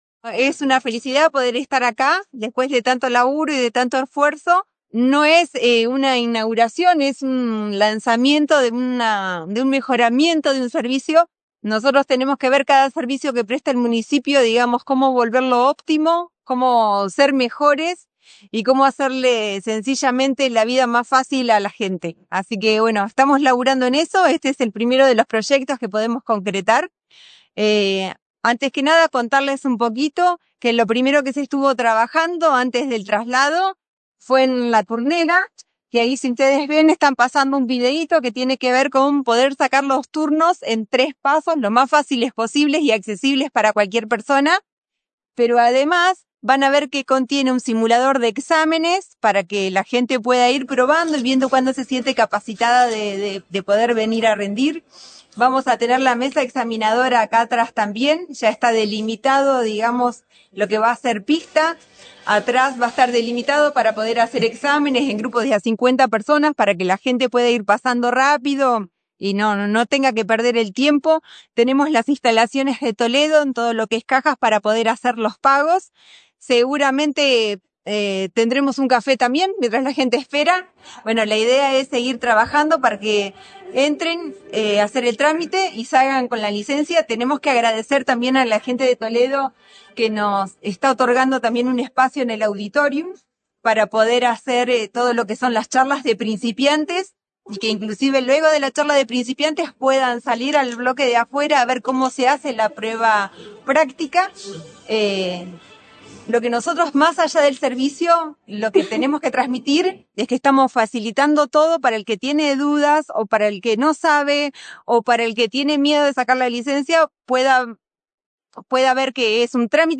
Con la presencia del intendente de Necochea, Arturo Rojas, este viernes se dieron a conocer las nuevas oficinas para la gestión de licencias de conducir, ubicadas en el Supermercado Toledo. Se trata de un paso clave que dio el municipio en materia de accesibilidad, ya que permitirá agilizar uno de los servicios más importantes mediante la concentración en un mismo lugar de todas las etapas del trámite, que anteriormente requerían recorrer varias dependencias separadas entre sí.
Durante la presentación, el jefe comunal fue acompañado por funcionarios del Ejecutivo, concejales y personal del área anfitriona, y agradeció especialmente al grupo empresarial por ceder sin costo para la comuna un espacio cómodo, accesible y equipado.